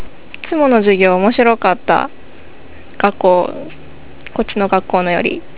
研修生の声　１